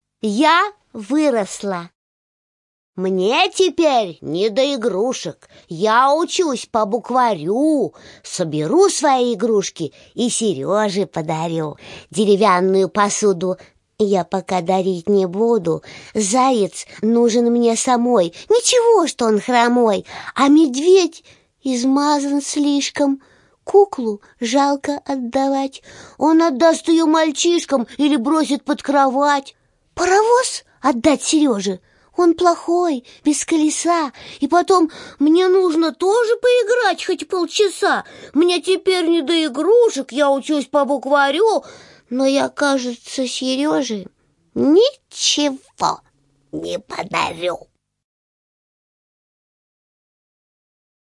Слушайте аудиостихотворение «Я выросла» Барто А. Л. на сайте Минисказка!